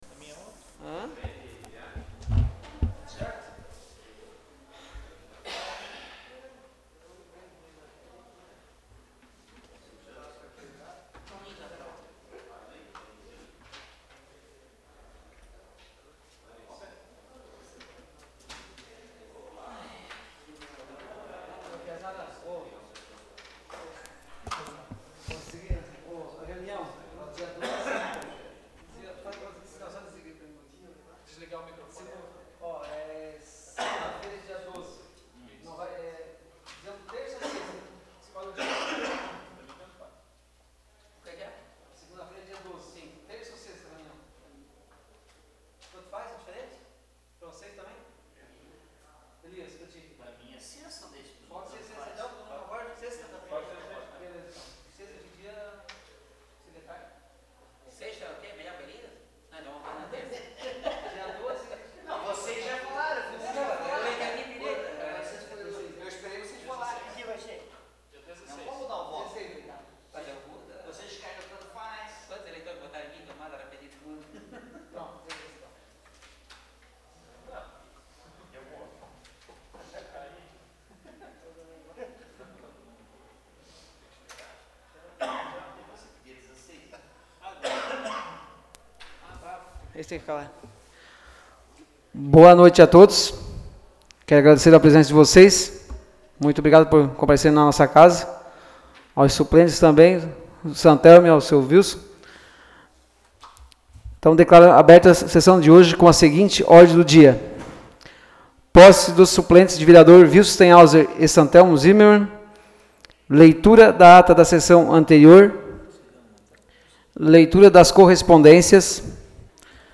Áudio da Sessão Ordinária realizada em 05 de outubro de 2015.